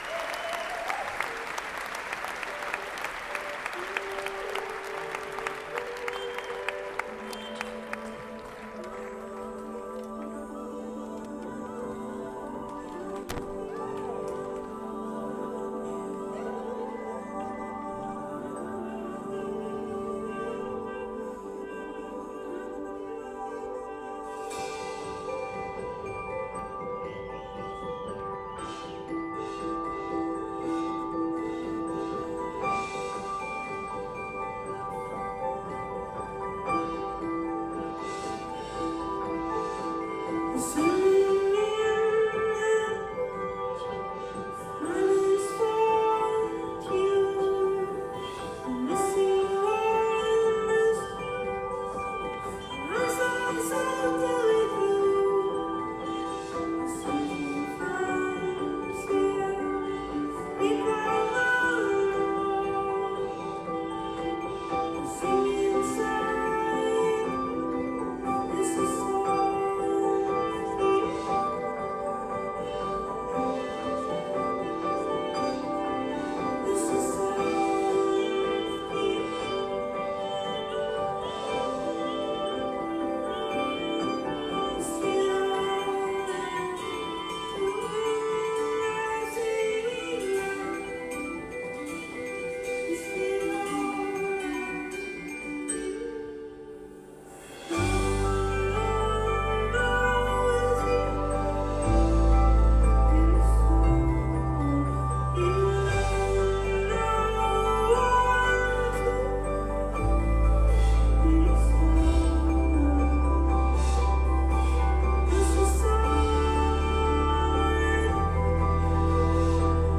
NEW Quadraphonic sound
Equipment : ZOOM H4 & DAT PCM M1 + ECM999PR